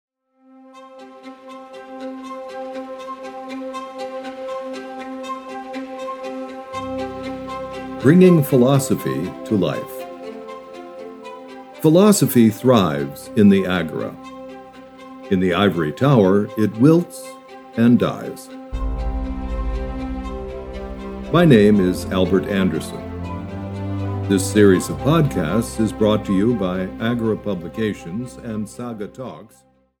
Might and Right (EN) audiokniha
Ukázka z knihy